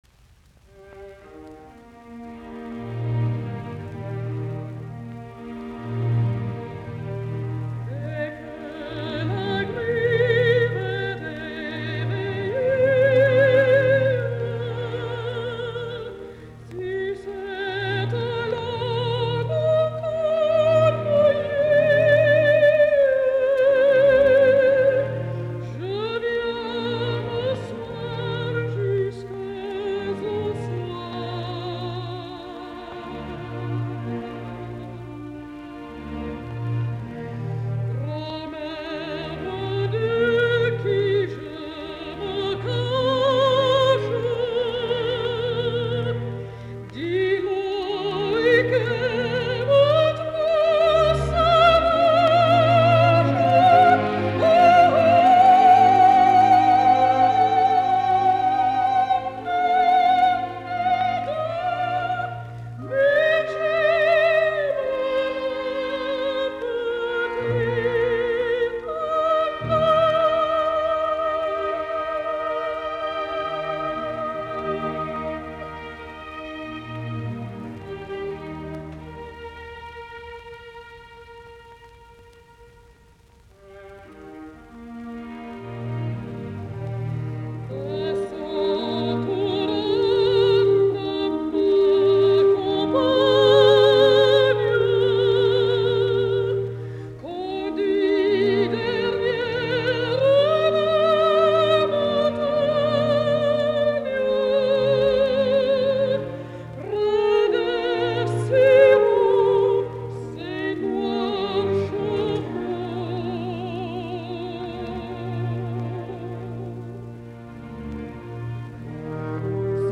Le jeune pâtre breton, S, ork., H65b
Soitinnus: Lauluääni (sopraano), ork.